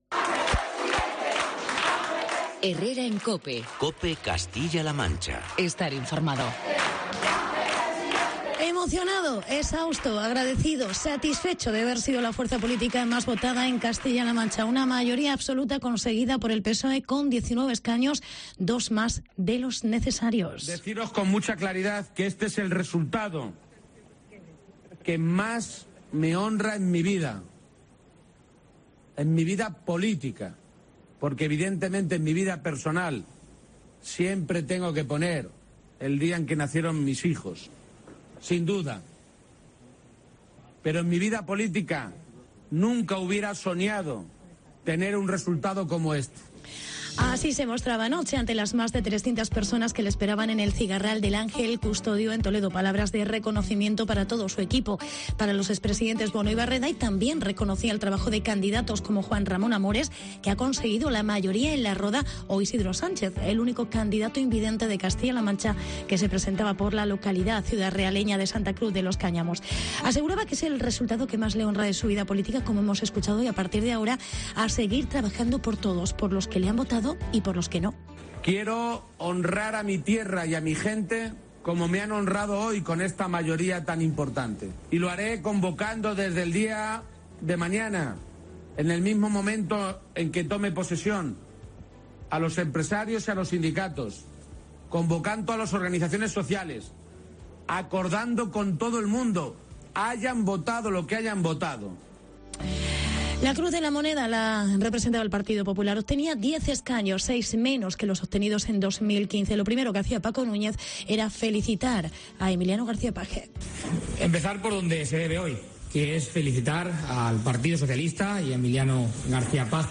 Page consigue mayoría absoluta . Reportaje sobre el resultado de las elecciones en CLM